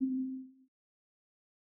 Machine ambient sounds
press.ogg